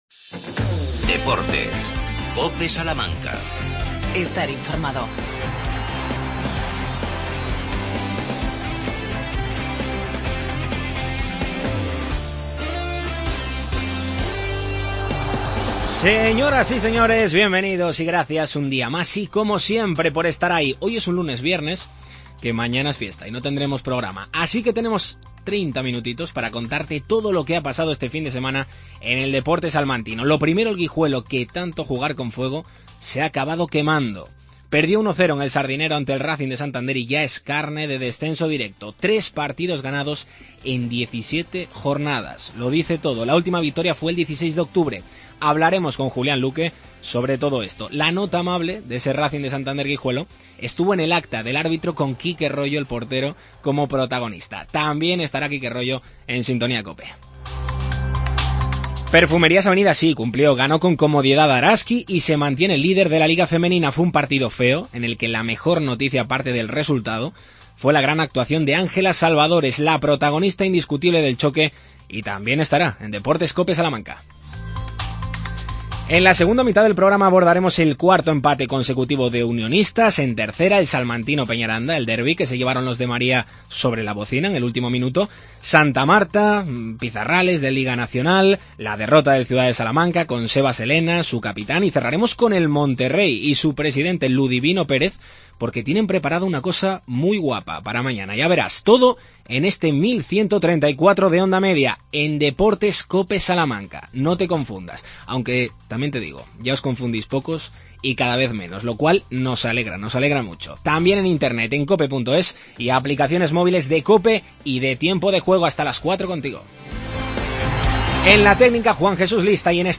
AUDIO: Racing de Santander 1 - Guijuelo. Entrevista